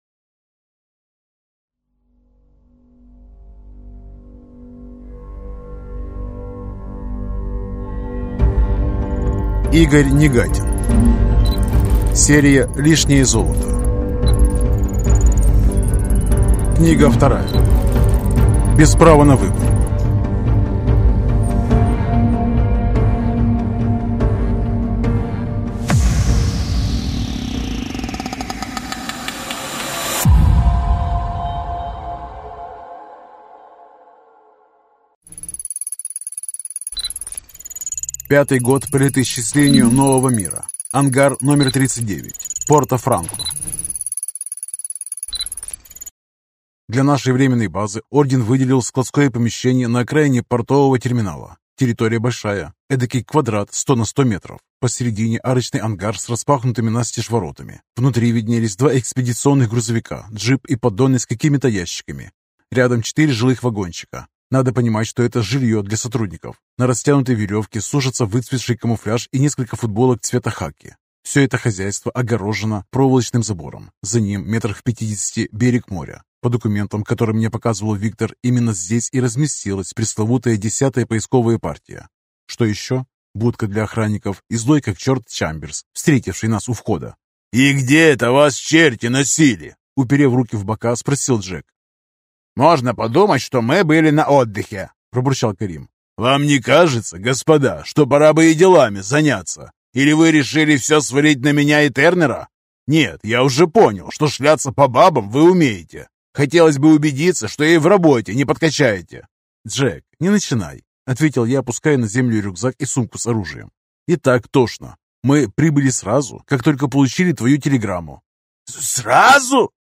Аудиокнига Лишнее золото. Без права на выбор | Библиотека аудиокниг